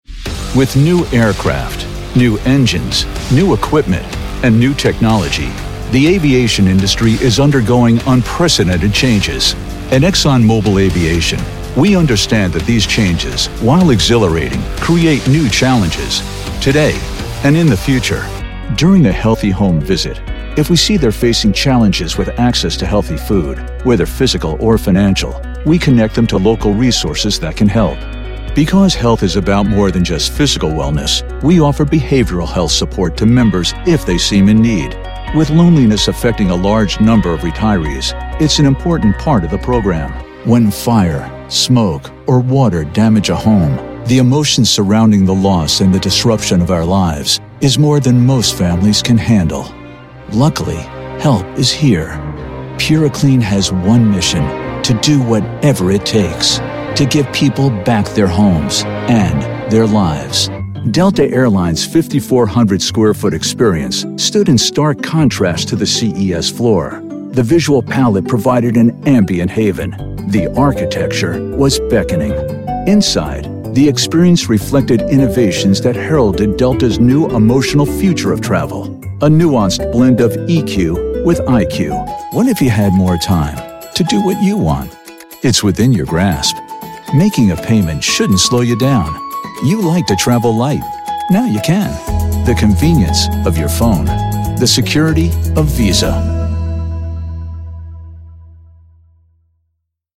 Male
Adult (30-50), Older Sound (50+)
His voice is like warm butter, smooth, professional, and articulate, yet approachable.
Narration
Corporate Narration
Words that describe my voice are Warm, Authoritative, Approachable.
0319Corporate_Narration_Demo.mp3